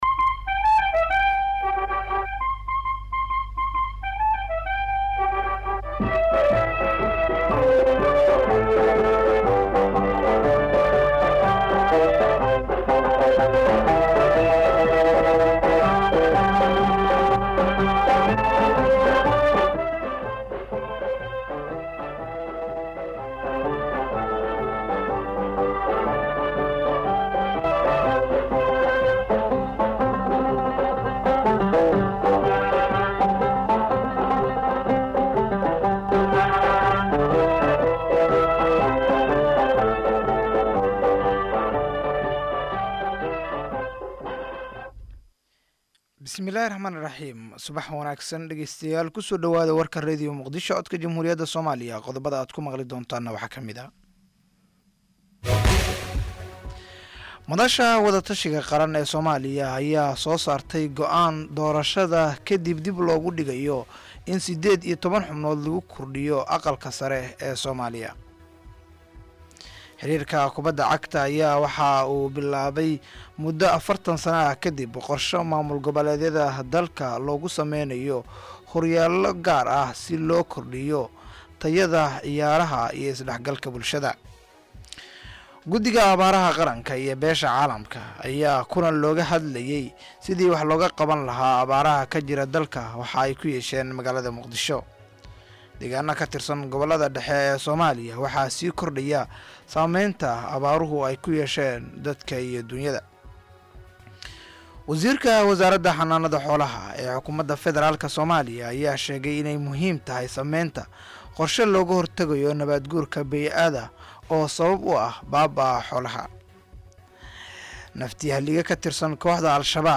Dhageyso warka subax ee Radio Muqdisho